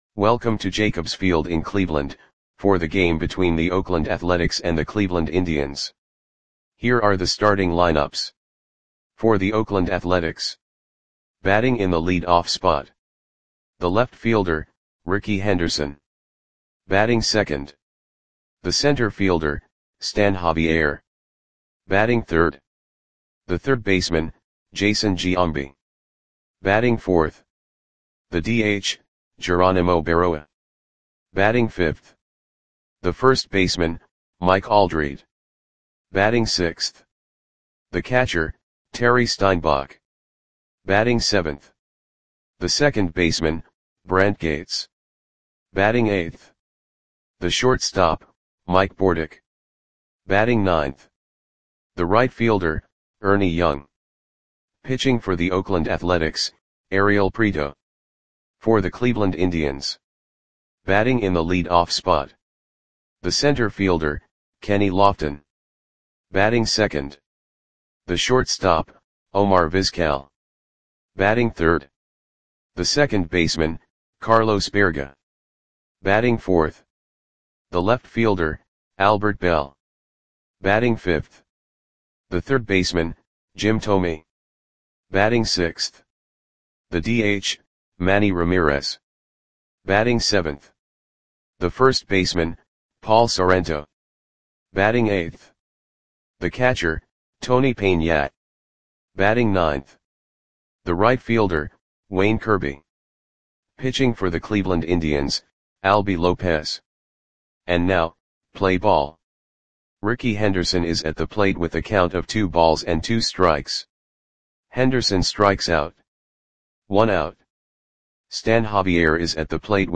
Audio Play-by-Play for Cleveland Indians on July 14, 1995
Click the button below to listen to the audio play-by-play.